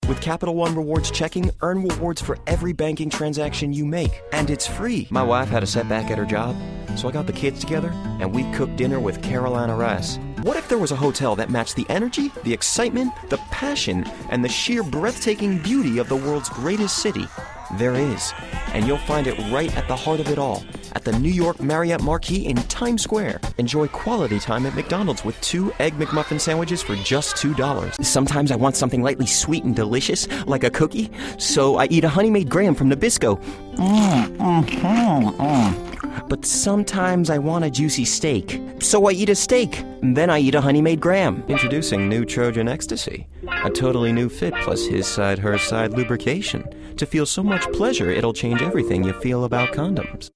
Young, Mid-Range Mid 20s-Mid 30s
Sprechprobe: Werbung (Muttersprache):